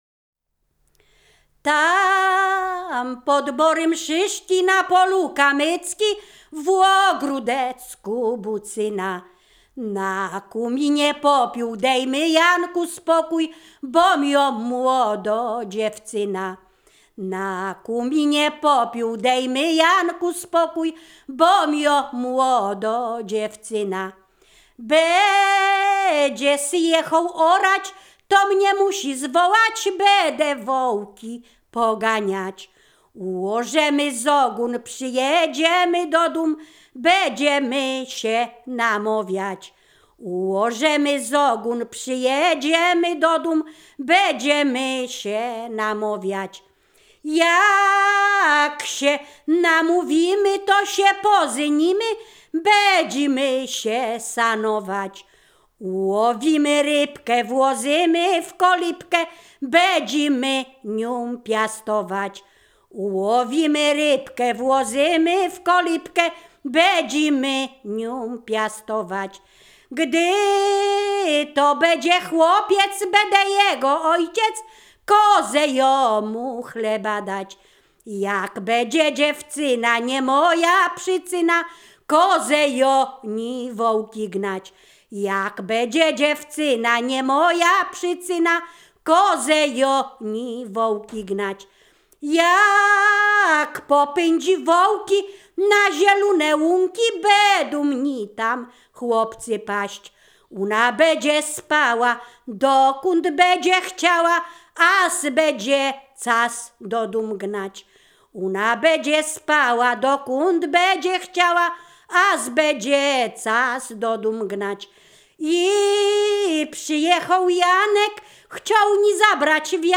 województwo mazowieckie, powiat przysuski, gmina Rusinów, wieś Brogowa
liryczne miłosne